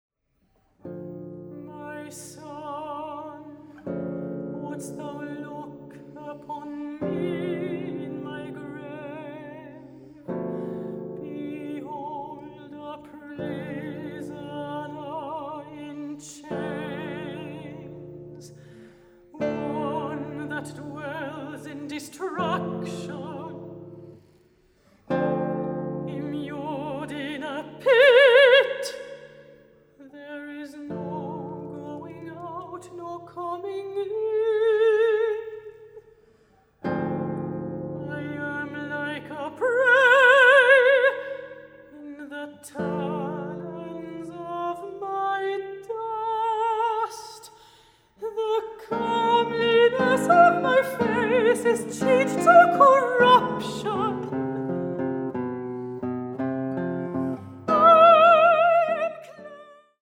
Gitarre
Gesang